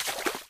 shallow_water.ogg